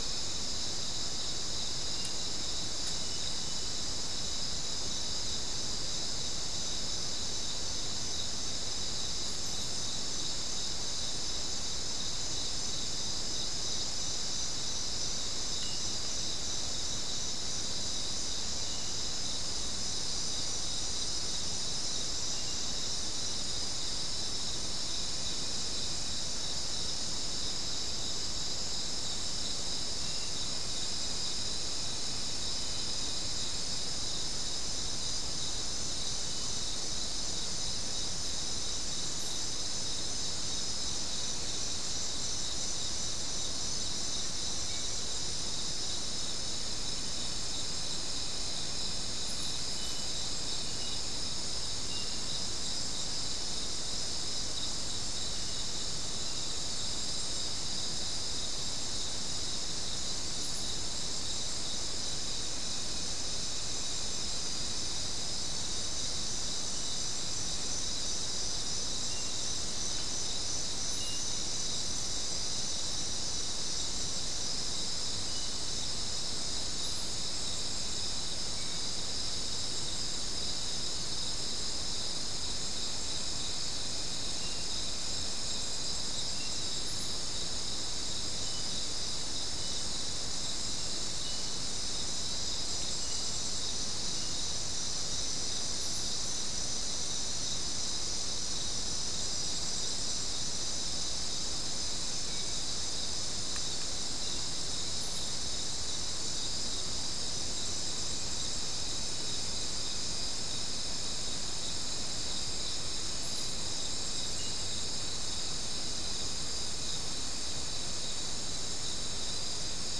Soundscape Recording Location: South America: Guyana: Sandstone: 3
Recorder: SM3